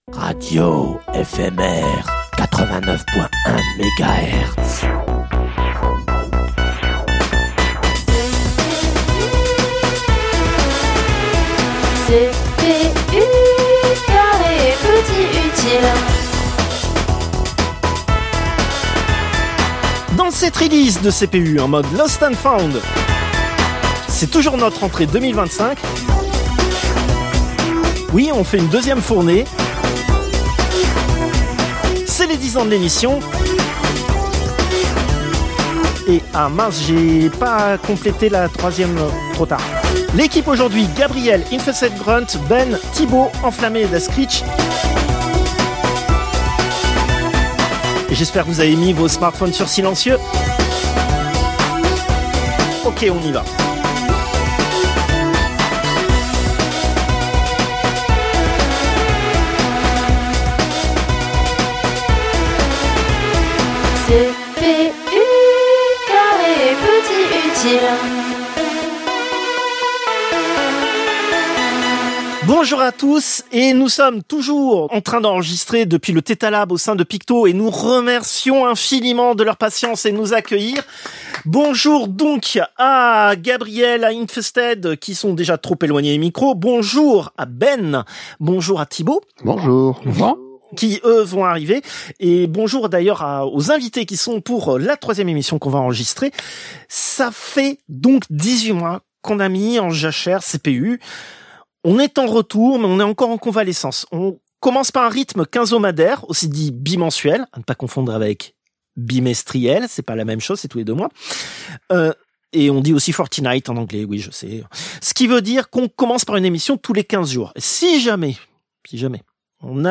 Plateaux enregistrés depuis le Tetalab chez Picto.